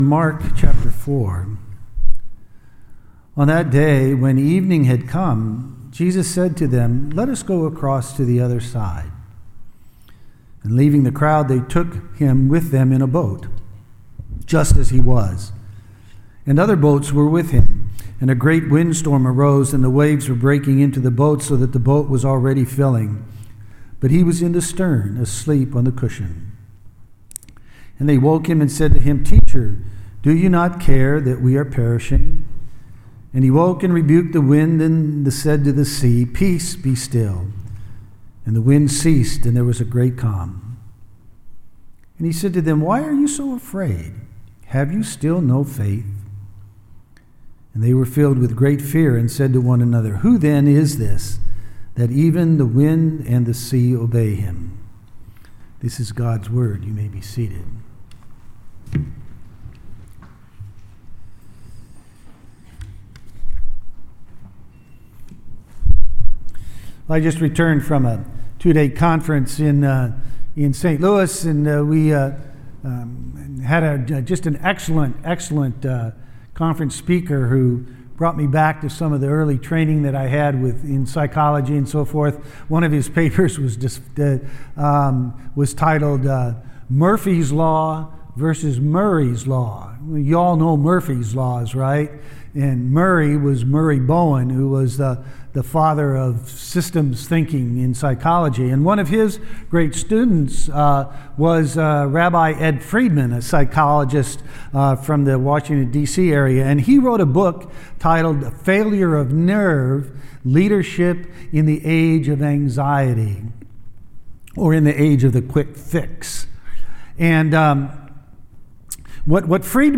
Sermon-6-24-18.mp3